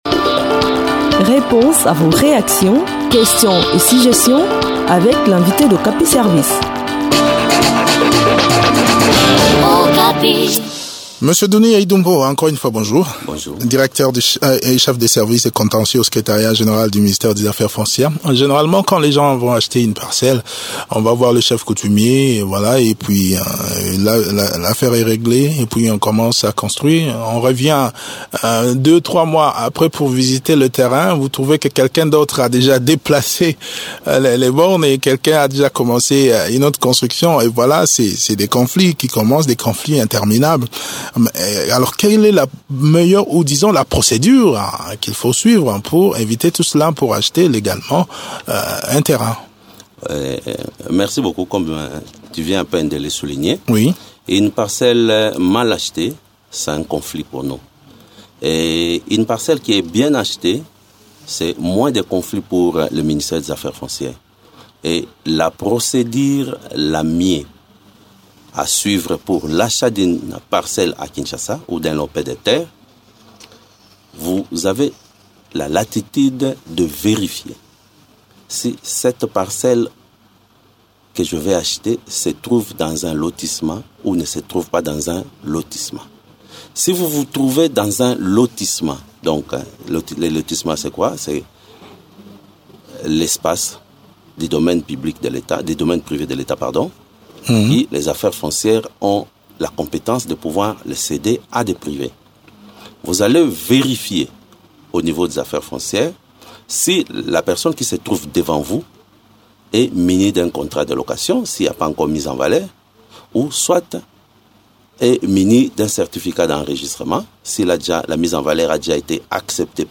Des éléments de réponse dans cette interview